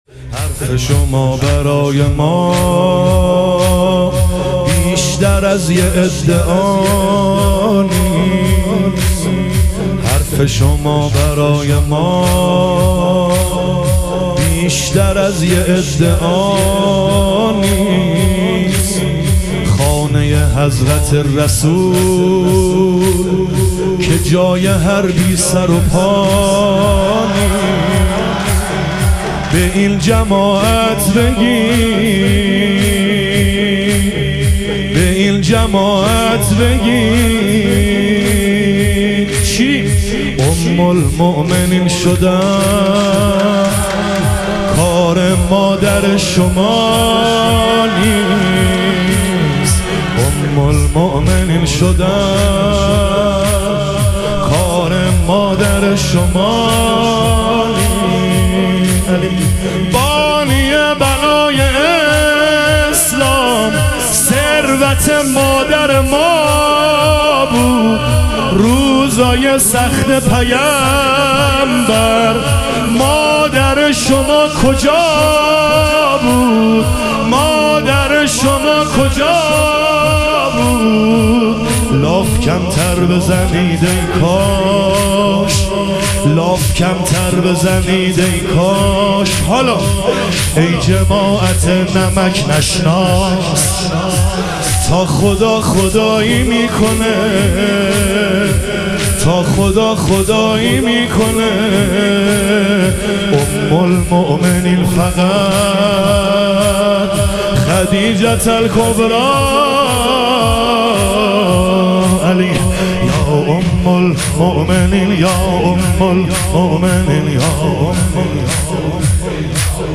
شهادت حضرت خدیجه علیها سلام - تک